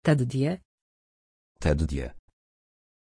Pronunciation of Teddie
pronunciation-teddie-pl.mp3